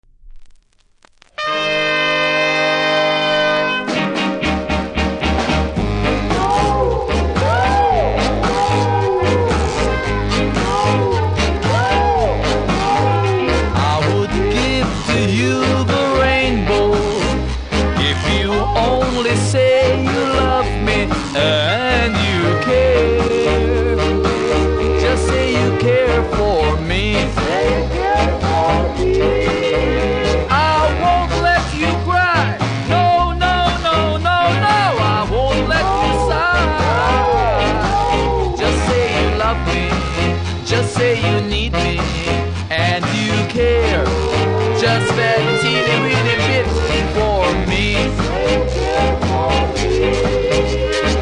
うすキズ多めですがノイズはそれほど感じなくプレイはOKレベルだと思いますので試聴で確認下さい。
両面出だしの無録音部分は音がないのでノイズ感じます。